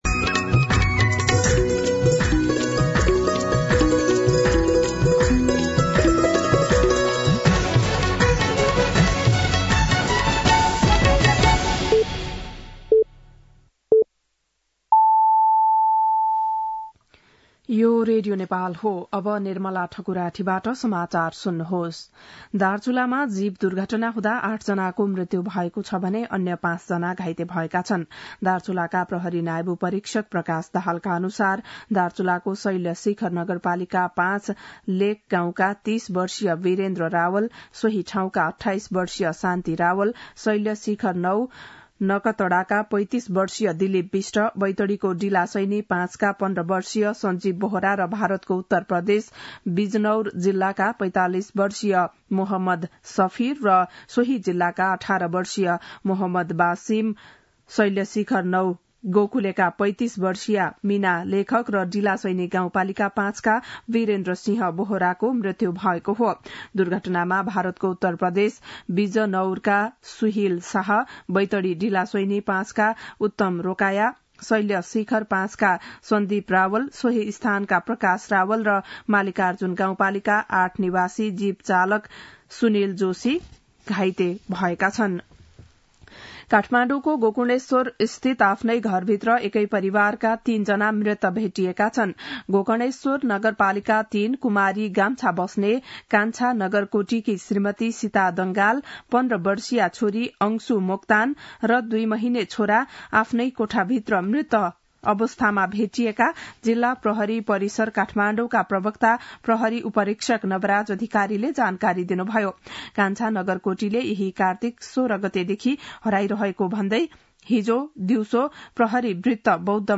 11-am-Nepali-News-3.mp3